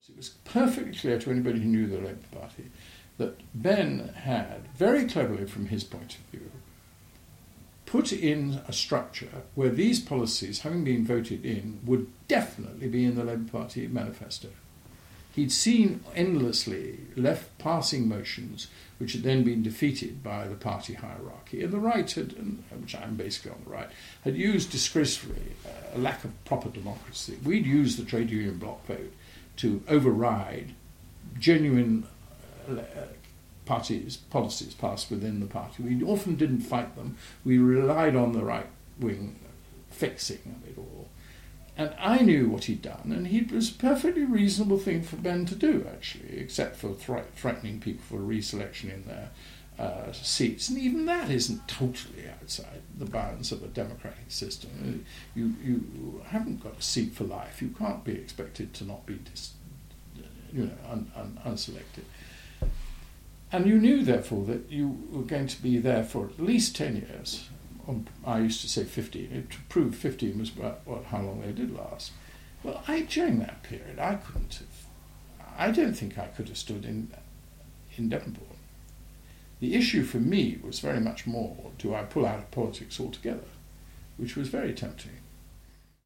Our oral history project features many former Labour MPs’ recollections of leadership contests, and the consequences of them, particularly in the 1980s as the party grappled with life in opposition and internal divisions between left and right.
David Owen, former Foreign Secretary and one of the ‘Gang of Four’ who formed the breakaway SDP, could understand the reasons behind the strategy of the left as he describes in this audioclip:
david-owen-labour-leadership-re-imagined.mp3